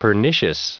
1667_pernicious.ogg